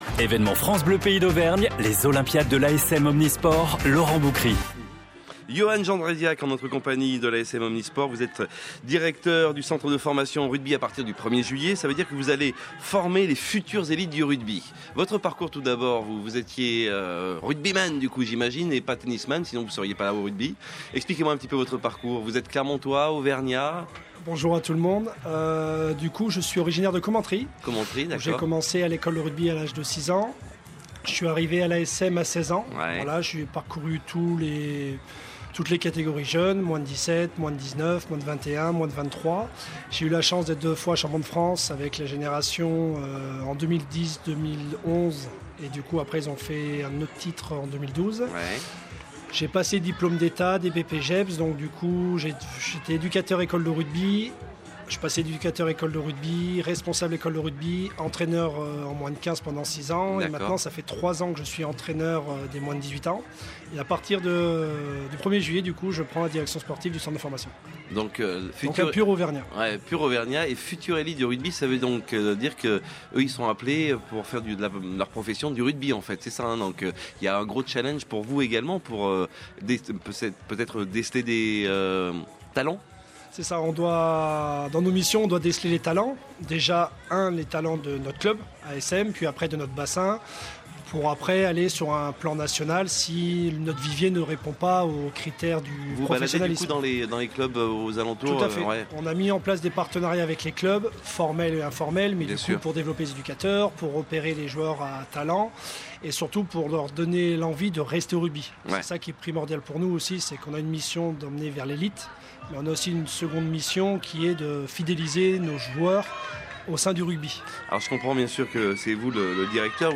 Le vendredi 23 juin dernier, dans le cadre de nos Olympiades ASM, France Bleu Pays d’Auvergne s’est installée à la Gauthière pour diffuser une émission spéciale ASM en direct, de 16h à 19h.
Jusqu’au 28 août, retrouvez les 14 interviews extraites de cette émission spéciale.